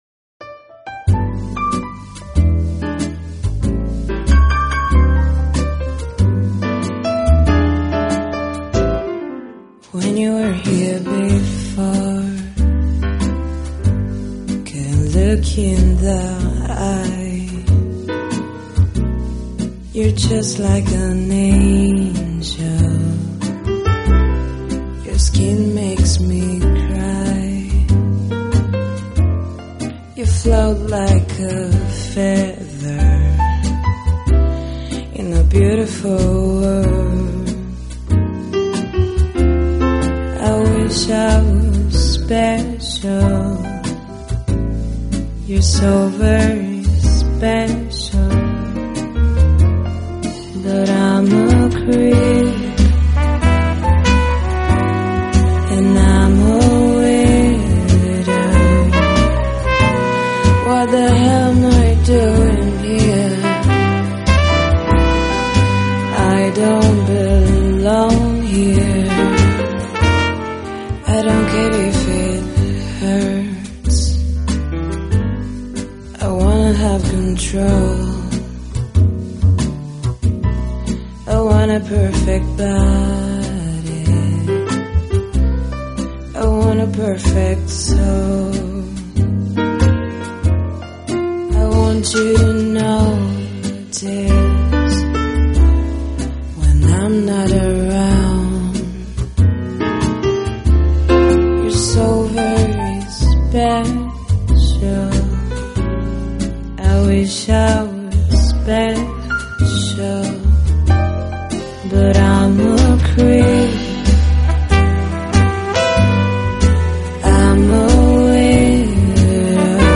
这张CD将90年代最炙手可热的歌曲用爵士重新演绎。